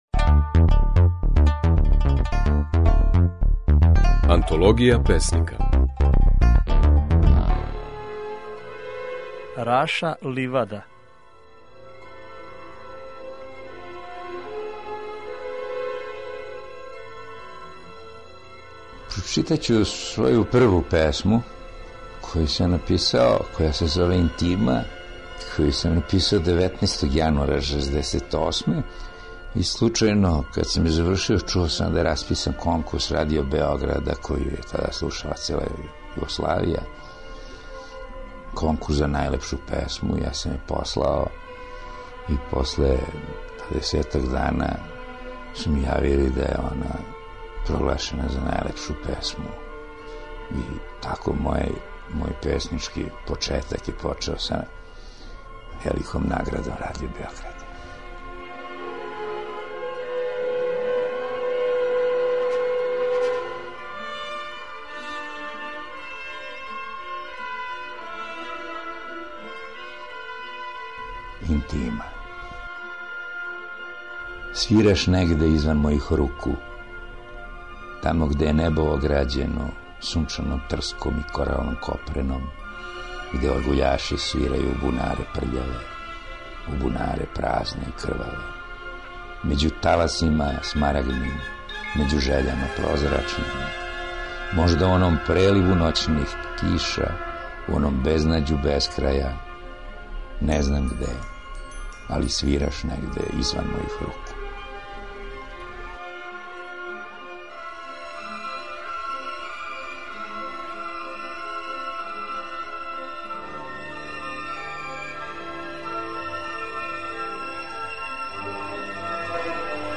Можете чути како своје стихове говори Раша Ливада.